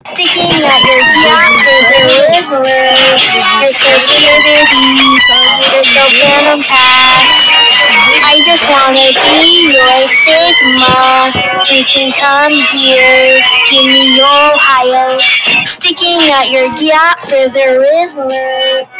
Really Loud Gyat Efecto de Sonido Descargar
Voice Record Soundboard3389 views